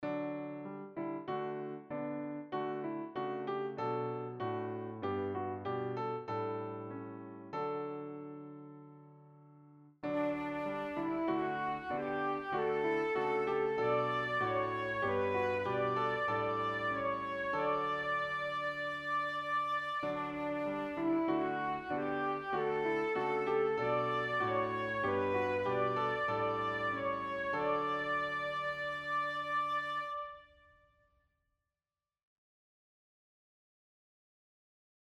Click here to listen to audio sample-Descant